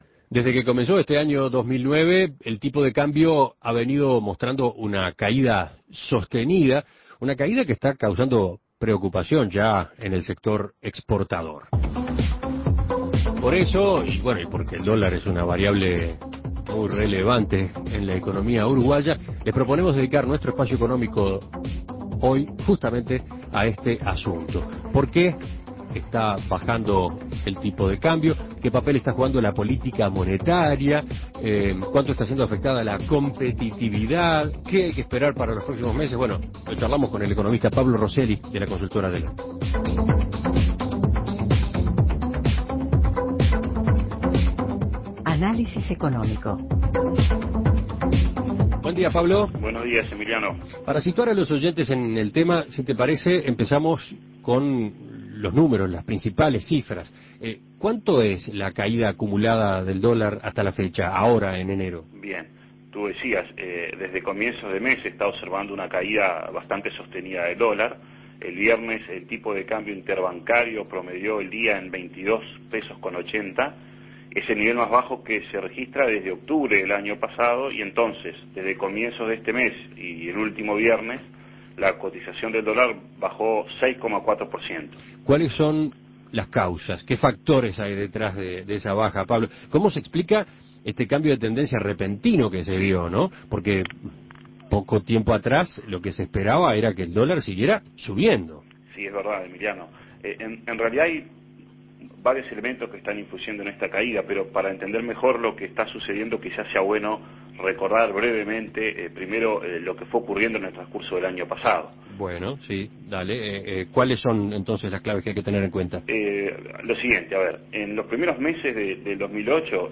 Análisis Económico ¿A qué obedece la caída del dólar en nuestro país y cuáles son las perspectivas para los próximos meses?